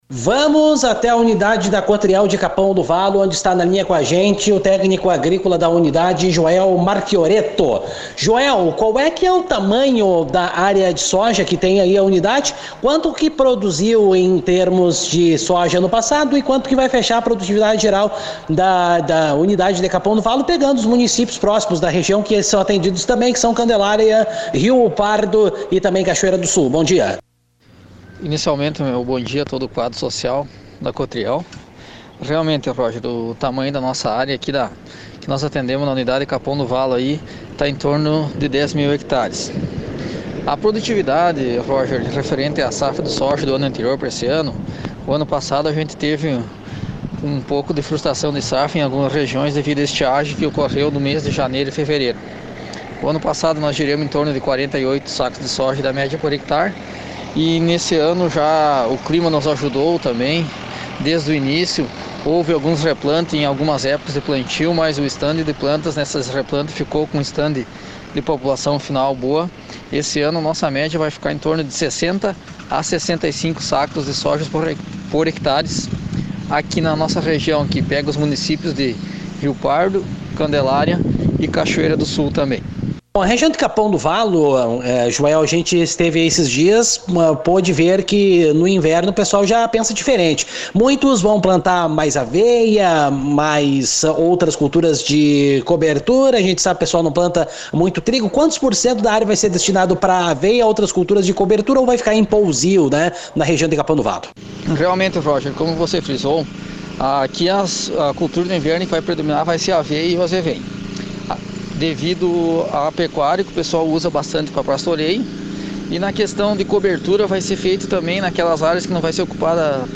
aqui a entrevista.